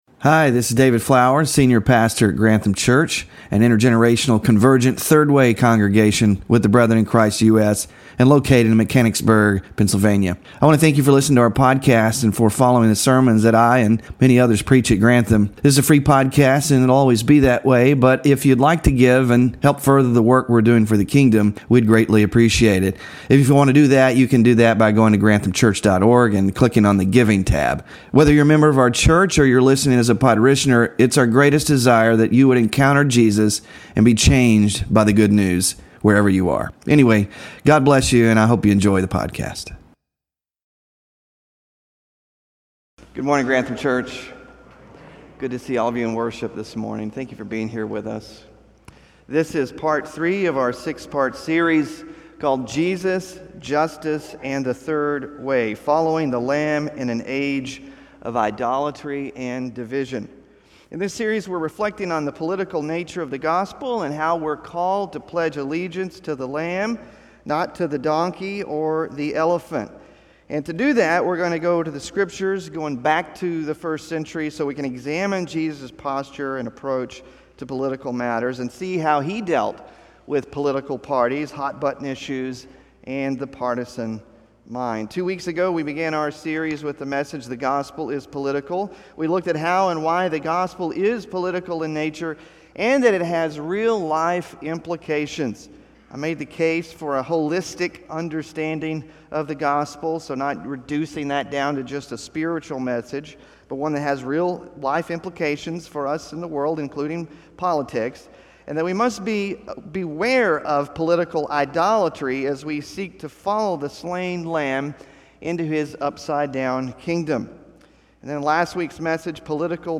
JESUS AND THE JUSTICE OF GOD SERMON SLIDES (3RD OF 6 IN SERIES) SMALL GROUP DISCUSSION QUESTIONS (10-20-24) BULLETIN (10-20-24)